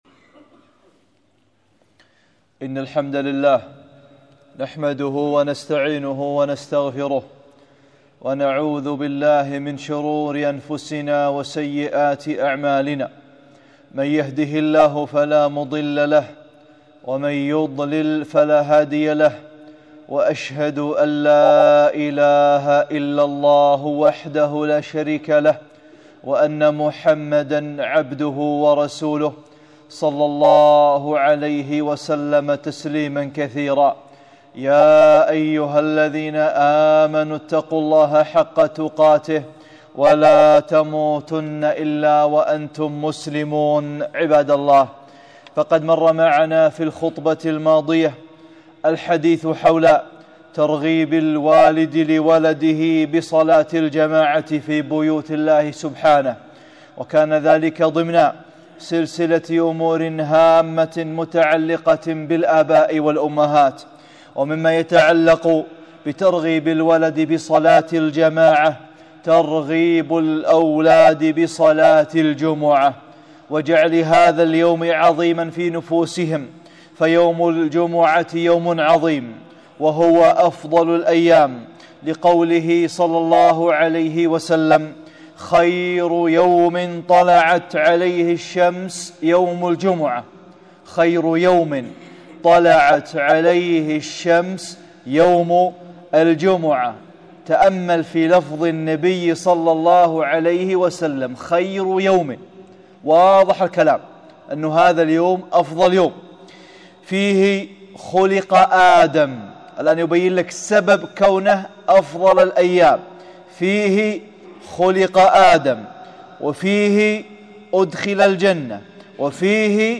(31) خطبة - صلاة الجمعة